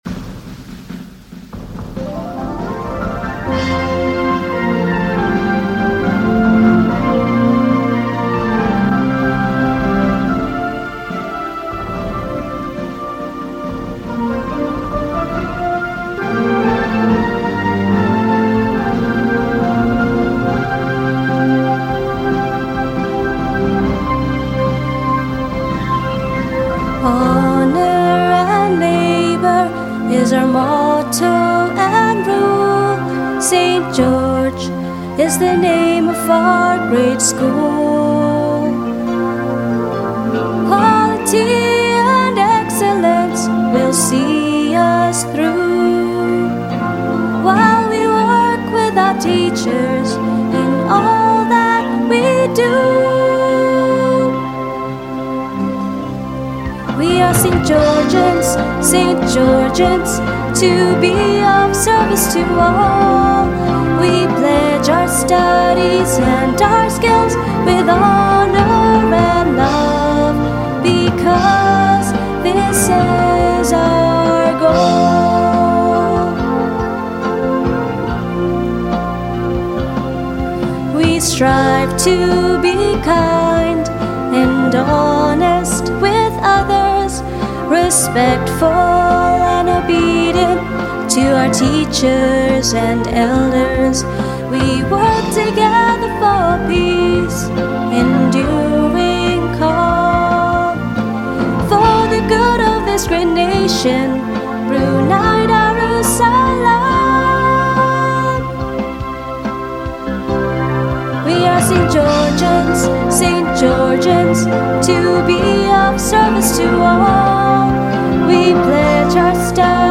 Click here to download School Song with Vocals in MP3 format. The vocals from Class of Year 2018.